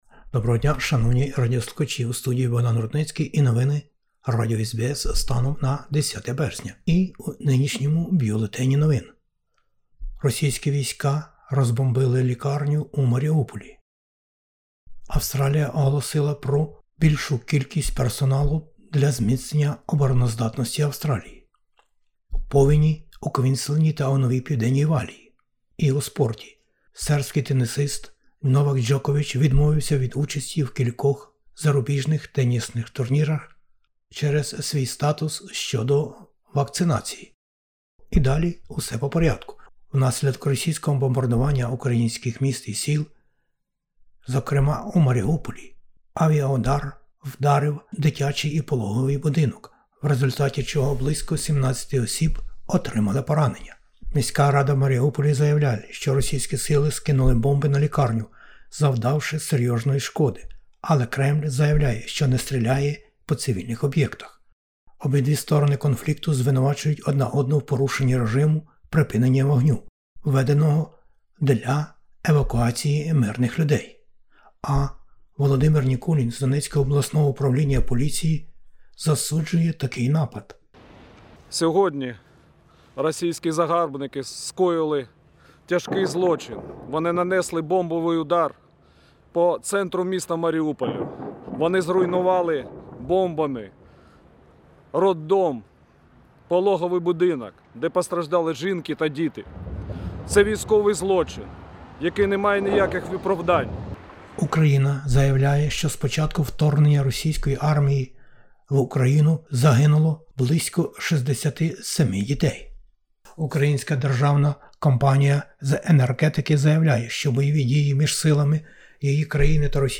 SBS бюлетень новин українською.